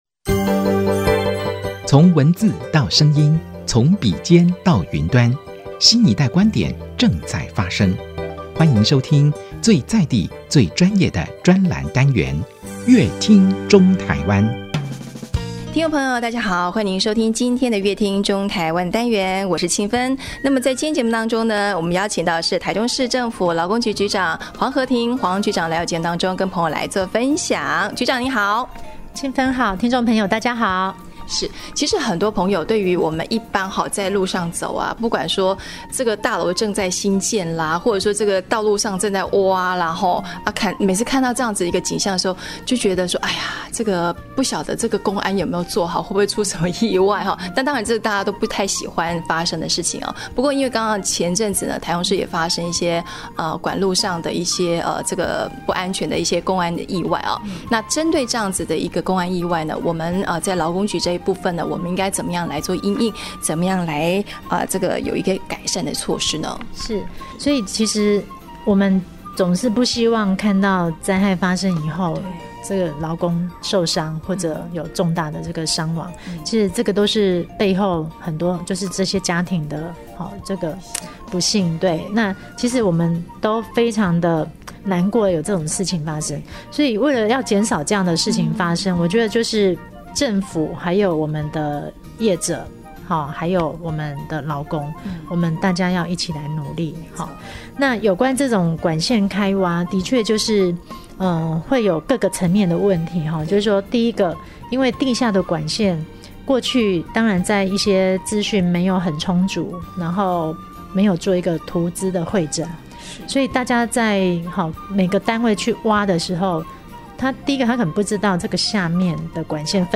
本集來賓：臺中市政府勞工局黃荷婷局長 本集主題：職災預防 勞工局提三道防線